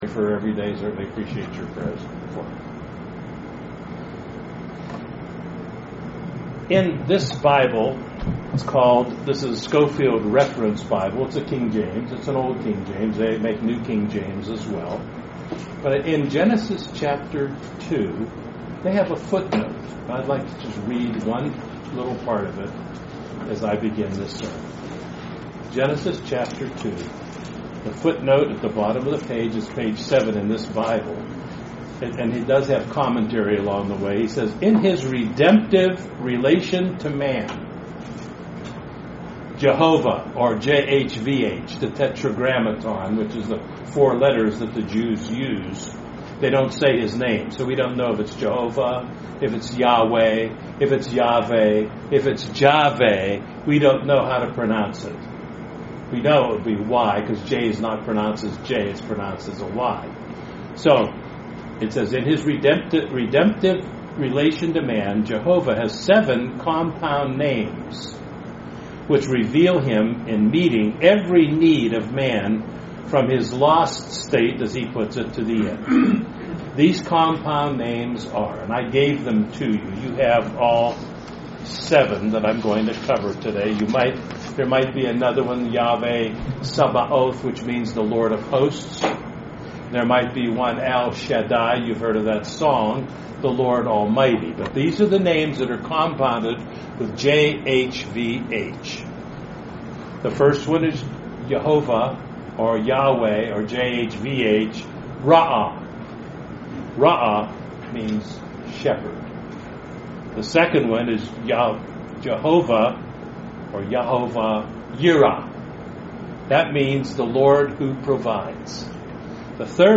Amazing study of Psalm 23 and how the 7 Hebrew names of God are found in this Psalm, and in the first few verses. A very uplifting and God-glorifying sermon.